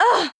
damage_flying.wav